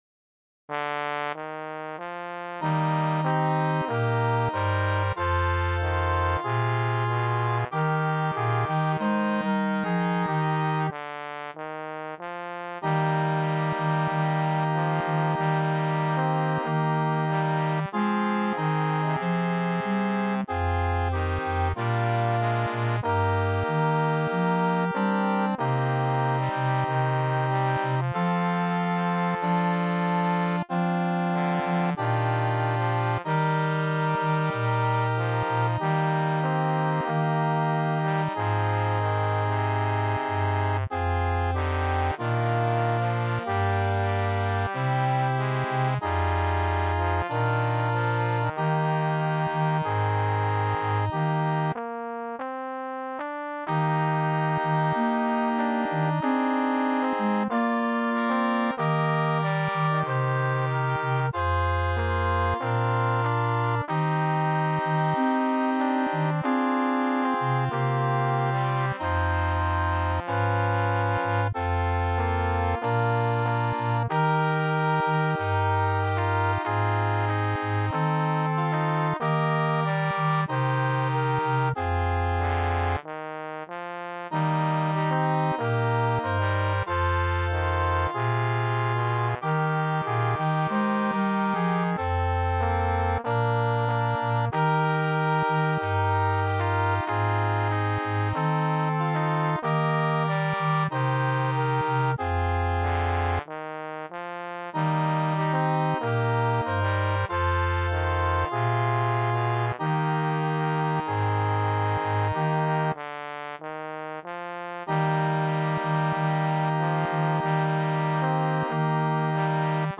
SATB (4 voices mixed) ; Full score.
Tonality: E flat major